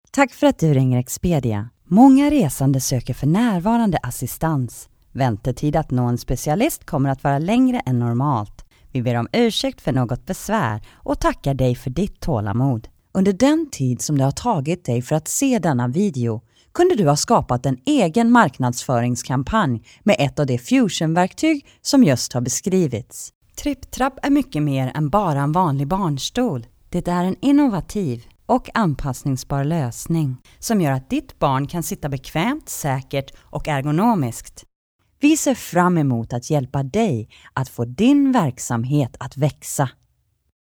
Swedish, North American, Positive, Warm, Smooth, Professional, Rich, Compassionate, Original, Worldly
Sprechprobe: Industrie (Muttersprache):